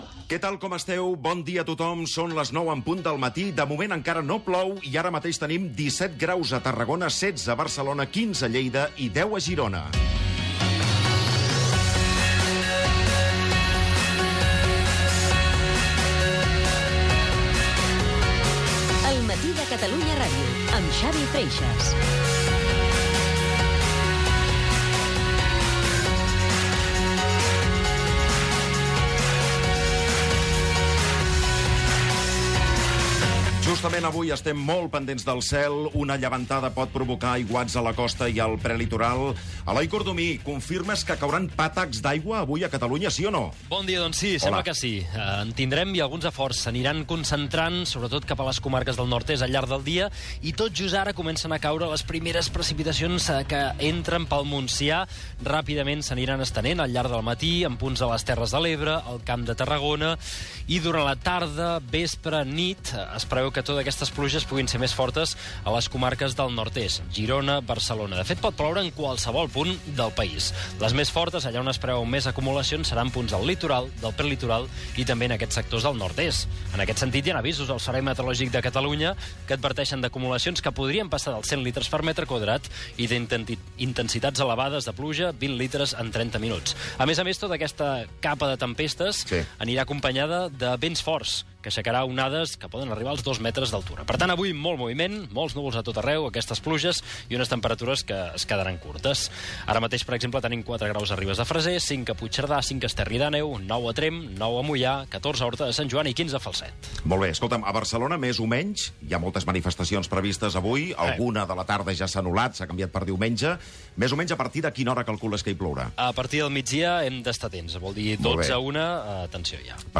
El matí de Catalunya Ràdio Gènere radiofònic Info-entreteniment